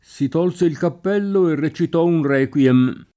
Requiem aeternam [lat. r$kULem et$rnam] tit. m. o f. (in it.) — anche accorciato in Requiem (s. m. o f.); e questo, quando non sia inteso come tit., di regola con r- minusc.: si tolse il cappello, e recitò un requiem [
Si t0lSe il kapp$llo, e rre©it0 un r$kULem] (Soldati); messa di r.; il R. di Mozart; ecc. — cfr. requie